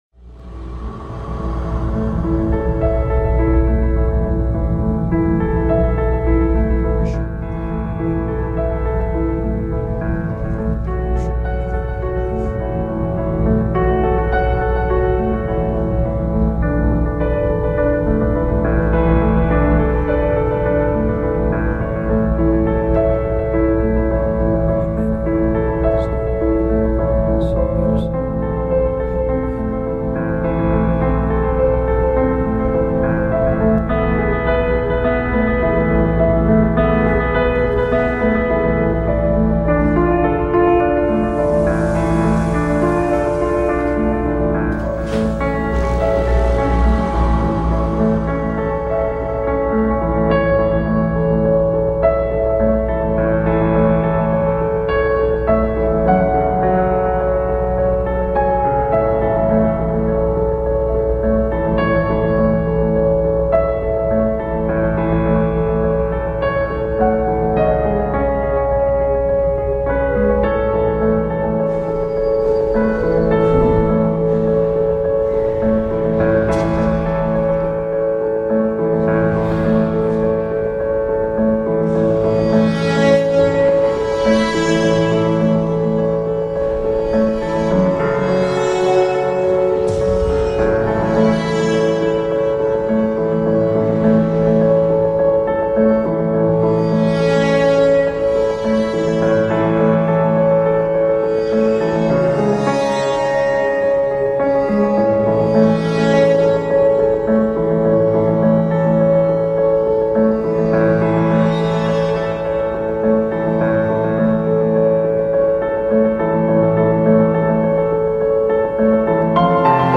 Die Klavierendmusik